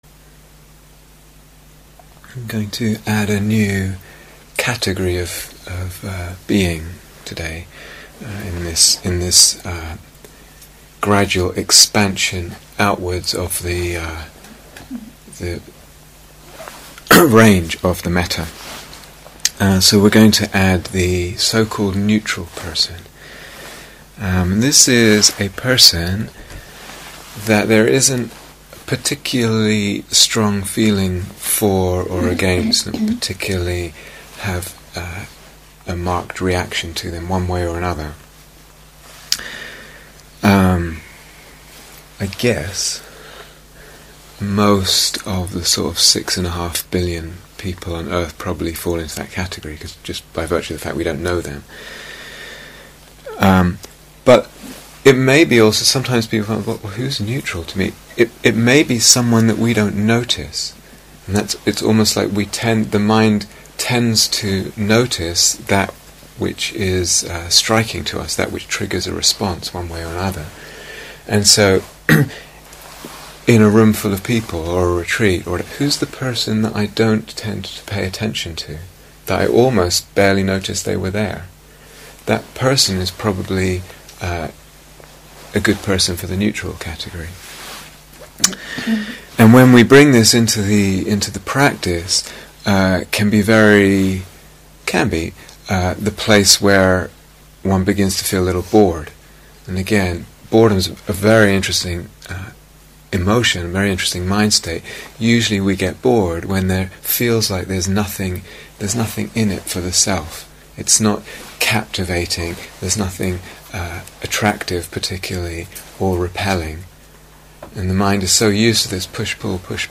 Fifth Instructions and Guided Mettā Meditation